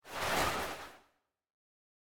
1.21.5 / assets / minecraft / sounds / mob / breeze / slide1.ogg
slide1.ogg